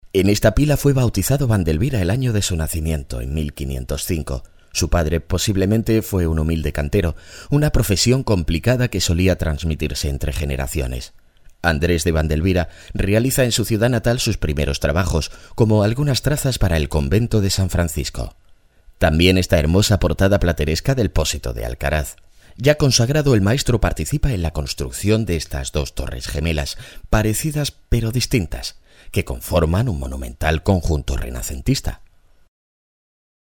Medium age male, warm, dynamic, confident and deep or young male, funny and very clear
kastilisch
Sprechprobe: Industrie (Muttersprache):